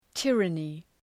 Προφορά
{‘tırənı}
tyranny.mp3